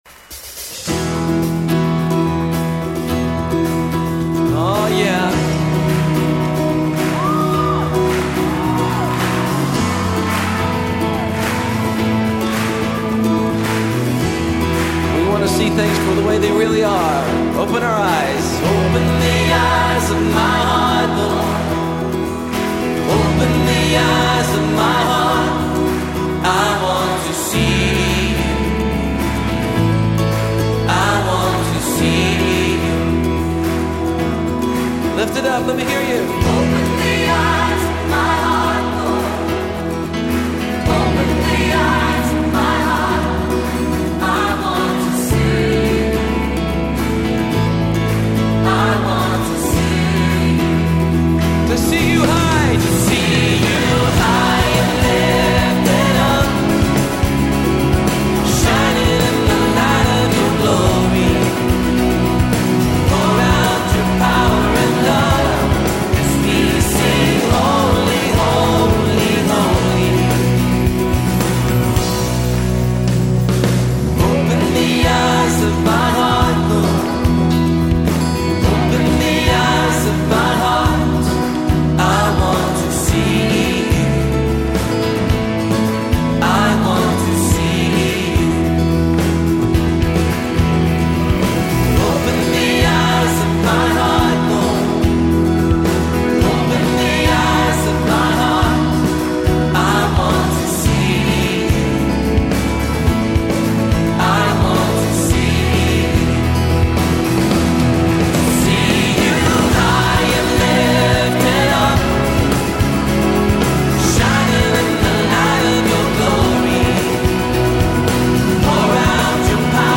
Devotional Songs - Open The Eyes Of My Heart